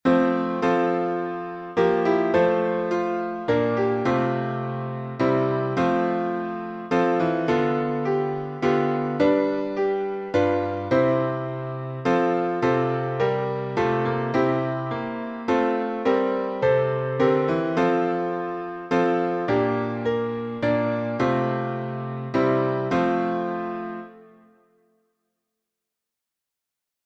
Words by Scottish Psalter, 1650; based on Psalm 23Tune: CRIMOND by Jessie S. Irvine (1836-1887);Key signature: F major (1 flat)Time signature: 3/4Meter: 8.6.8.6.(C.M.)Public Domain1.